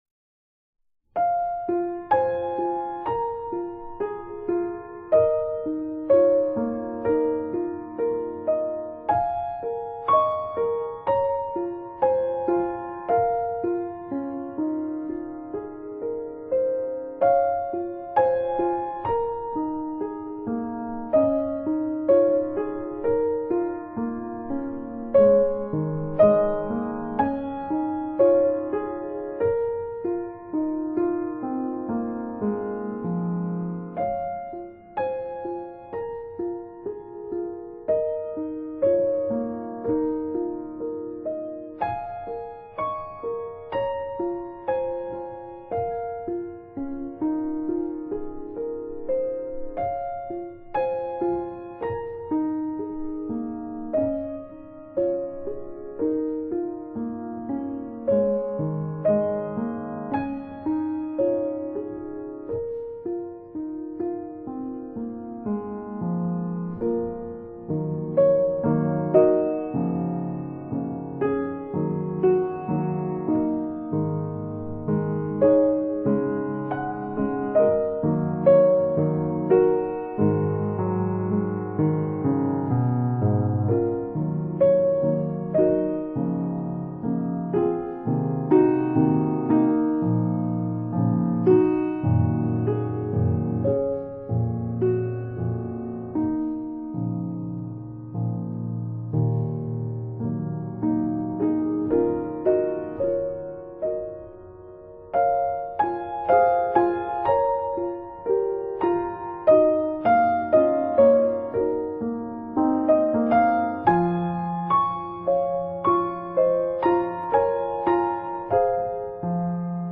清清澹澹的琴音獻給每一顆豐富的心靈。